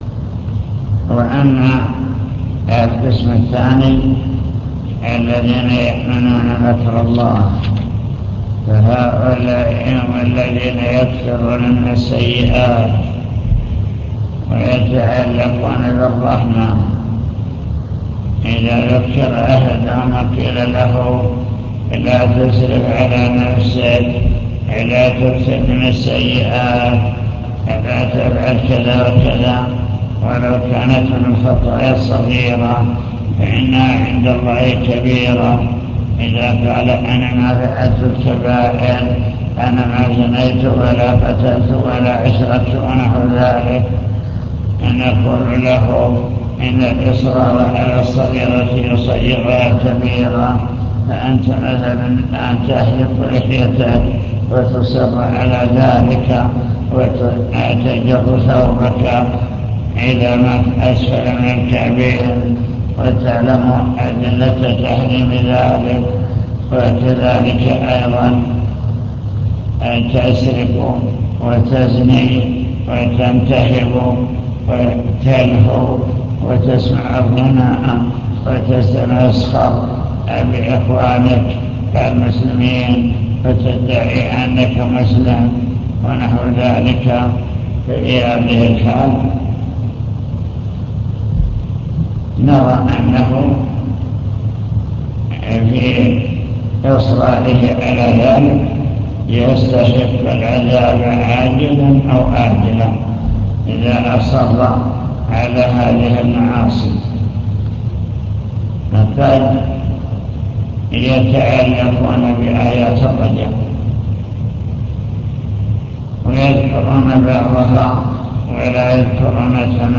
المكتبة الصوتية  تسجيلات - محاضرات ودروس  كتاب التوحيد للإمام محمد بن عبد الوهاب باب قول الله تعالى 'أفأمنوا مكر الله فلا يأمن مكر الله إلا القوم الخاسرون'